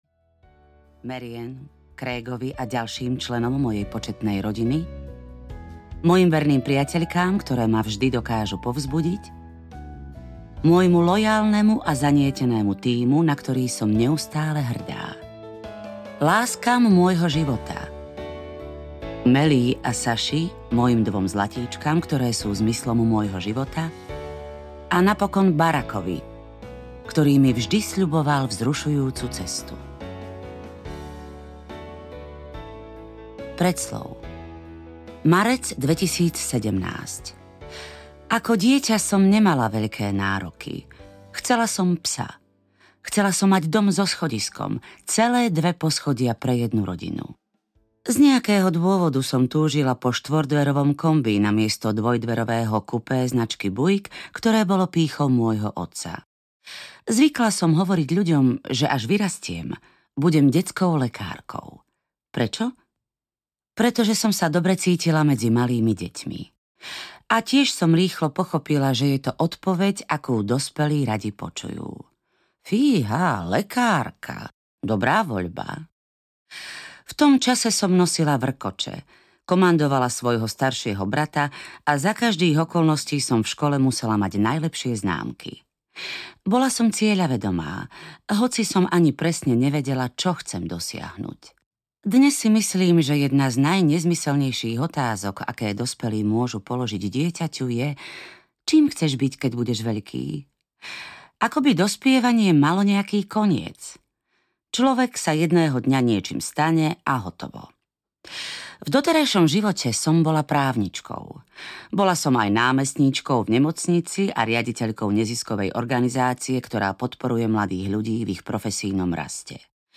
Môj príbeh audiokniha
Ukázka z knihy